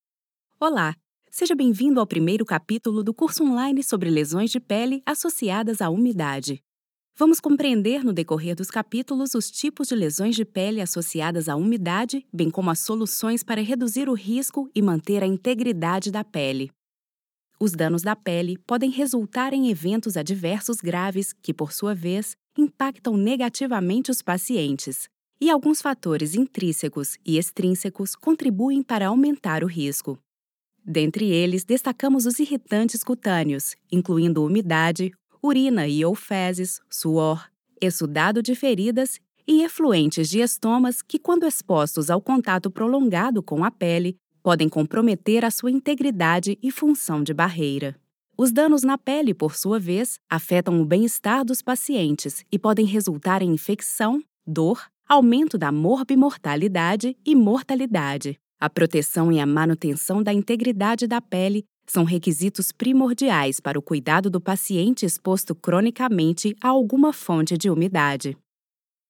Kommerziell, Junge, Sanft
E-learning